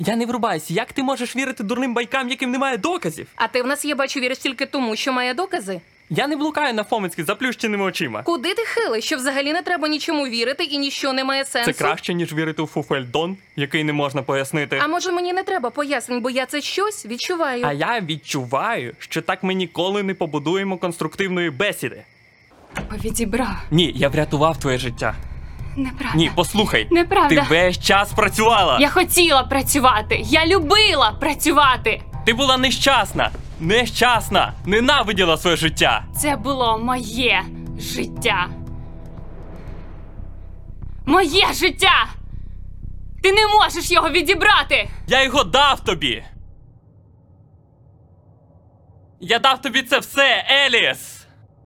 Commerciale, Naturelle, Polyvalente, Amicale, Enjouée